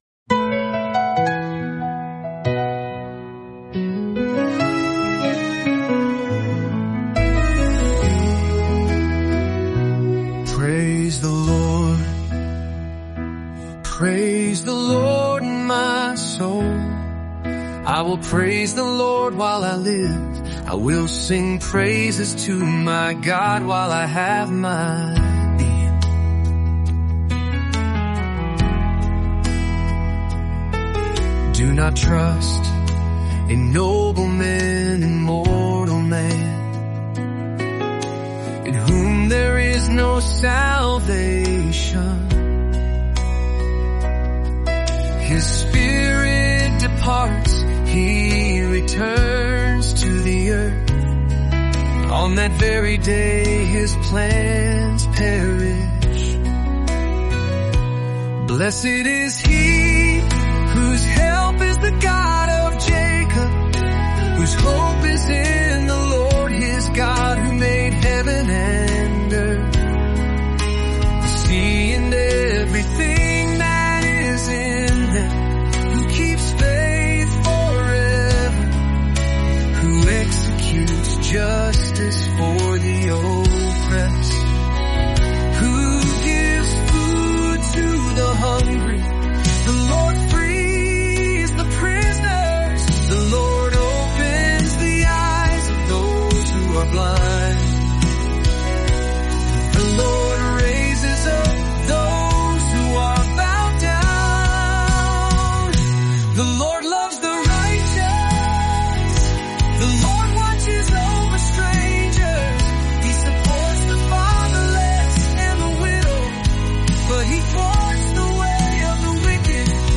Immerse yourself in the prayers, praises, and wisdom of Psalms in just 30 days through word-for-word Scripture songs.